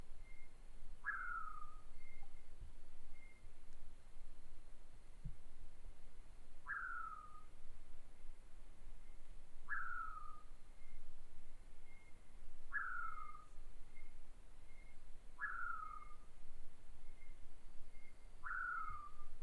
Atajacaminos Ocelado (Nyctiphrynus ocellatus)
Nombre en inglés: Ocellated Poorwill
Condición: Silvestre
Certeza: Observada, Vocalización Grabada